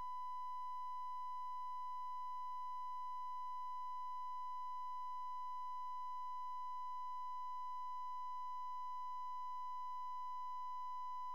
使用 音频分析器将麦克风输入(MIC3R)馈送到EVM (设置为600Ohm，1kHz音频，正弦)。
我使用PC以.wav格式获取了样本(Windows 7中的录音机)。
采样频率默认为44.1kHz
和馈入的以下范围(3mV至500mV) 1kHz音调。